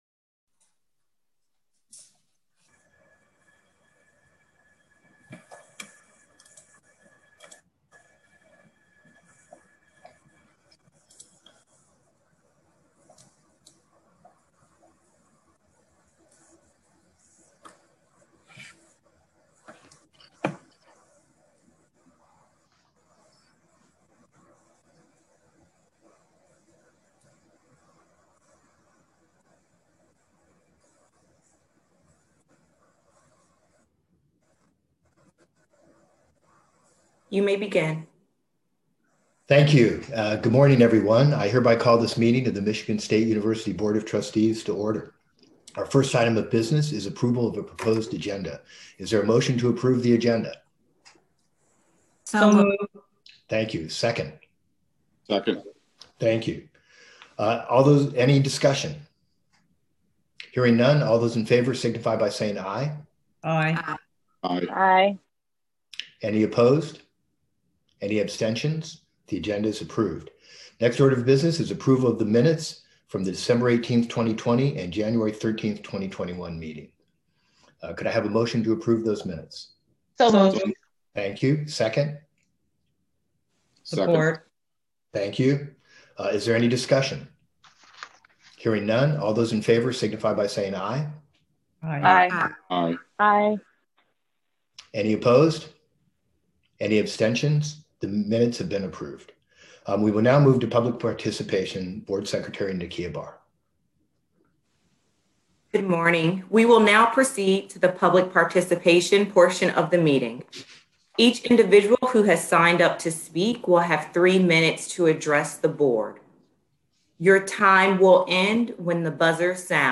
Where: Zoom